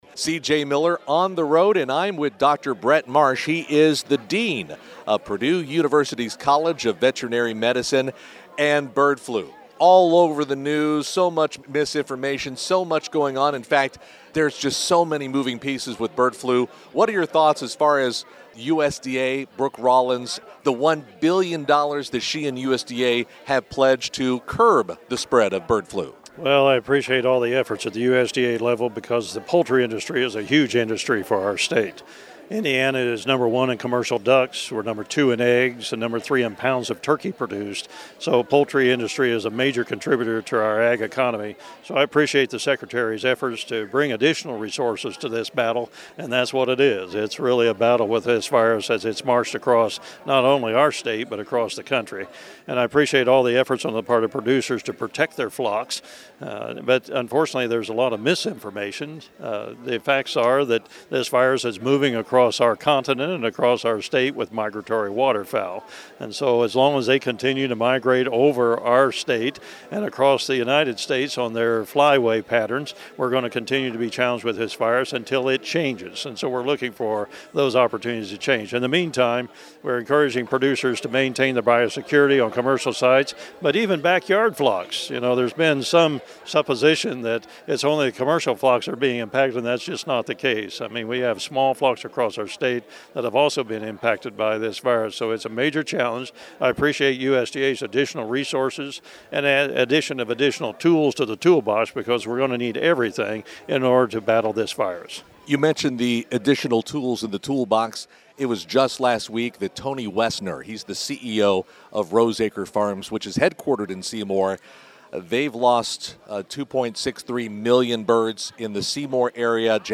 during the “Taste of Shelby County Agriculture” banquet last week in Shelbyville: